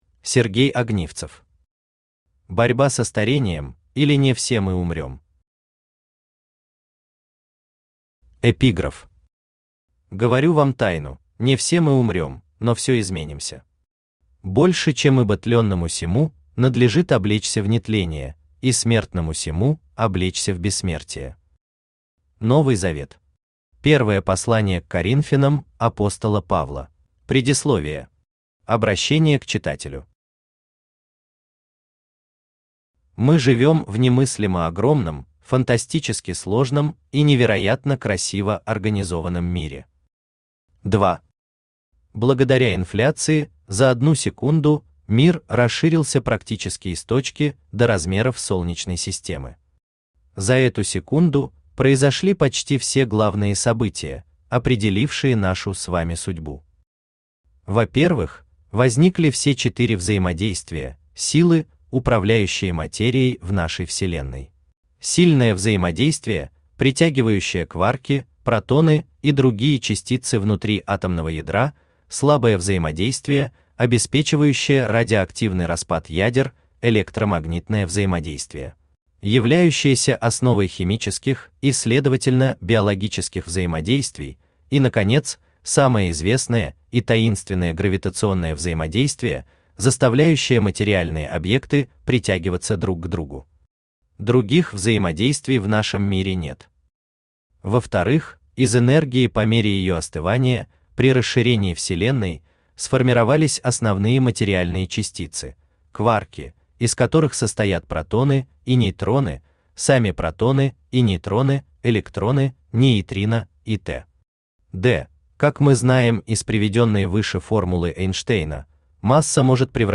Аудиокнига Борьба со старением, или Не все мы умрем…
Автор Сергей Огнивцев Читает аудиокнигу Авточтец ЛитРес.